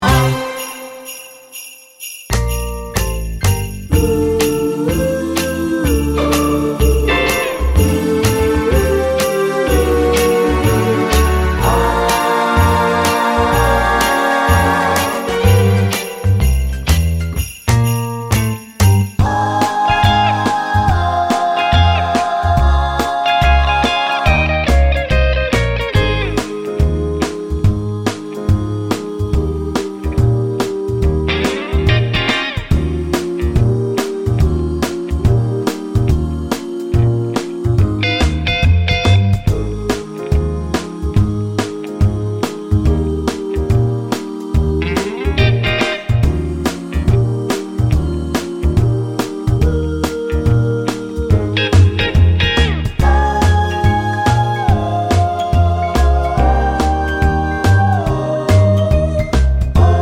Medley Christmas 2:20 Buy £1.50